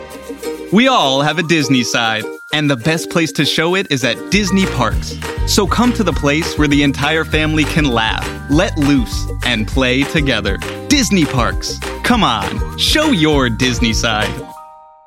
Disney Side - Commercial - Upbeat